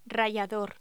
Locución: Rallador
Sonidos: Voz humana